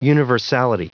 Prononciation du mot universality en anglais (fichier audio)
Prononciation du mot : universality